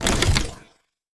sfx_select_card.mp3